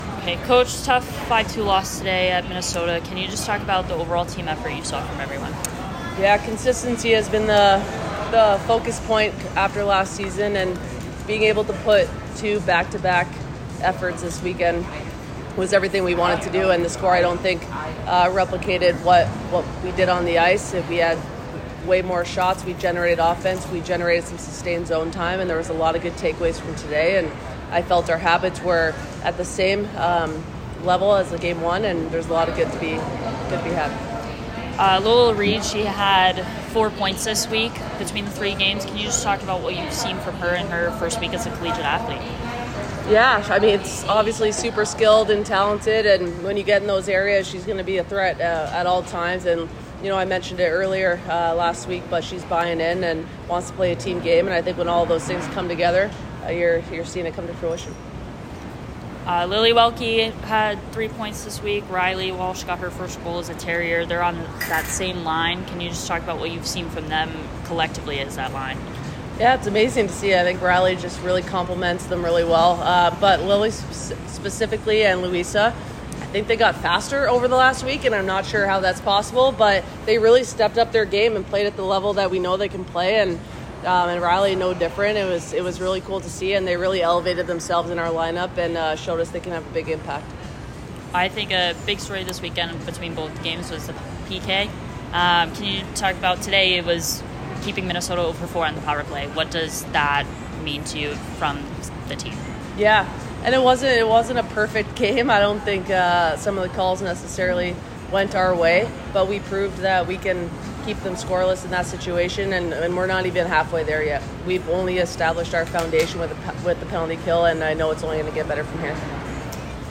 Minnesota Postgame Interview